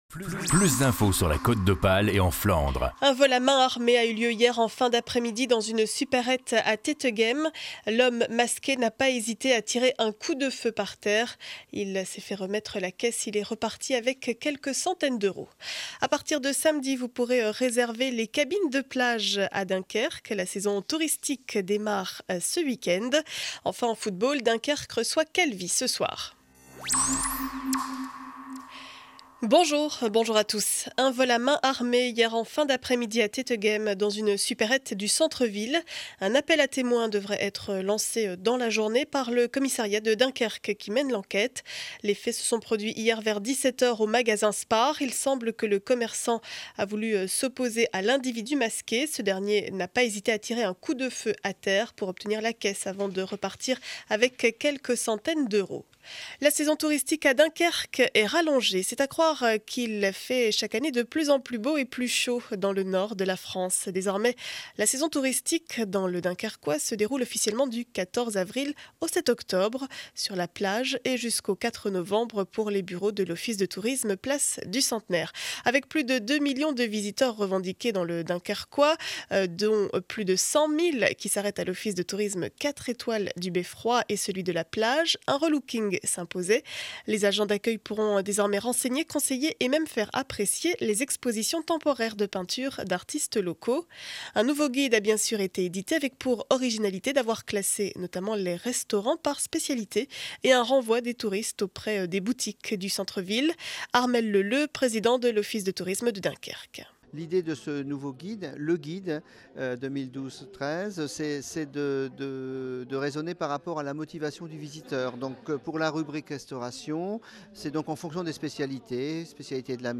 Journal du mercredi 11 avril 2012 7 heures 30 édition du Dunkerquois.